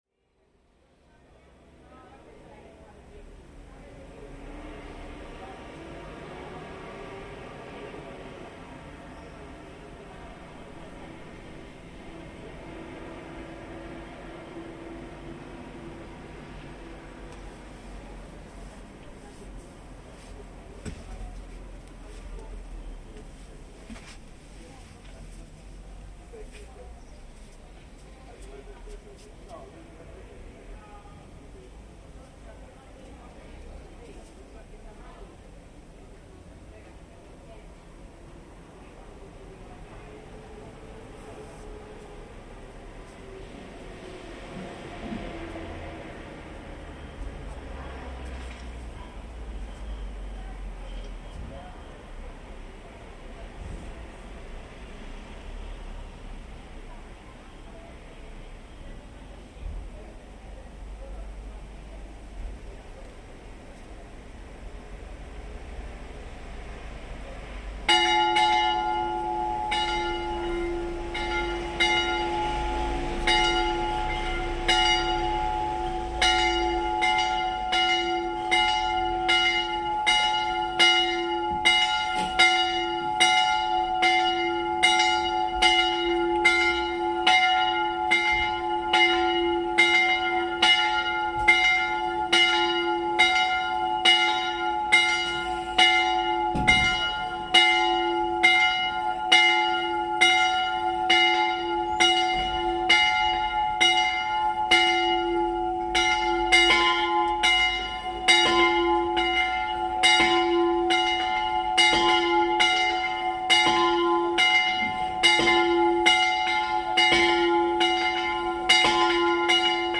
Listen to the sounds of the evening bells for mass ringing in the sleepy village of Pomonte, in the south-west of the island of Elba. The sun is beginning to set in late August after a long day on the beach, and the bells ring out across the sky.